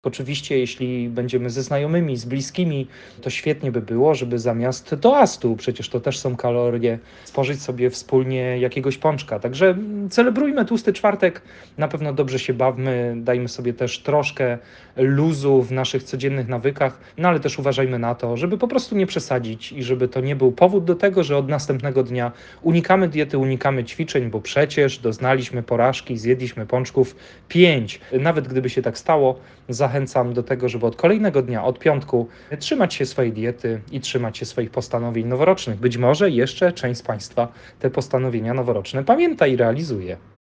Na te pytania odpowiada trener mentalny
– Unikajmy w Tłusty Czwartek kompulsywnego jedzenia, radzi trener mentalny.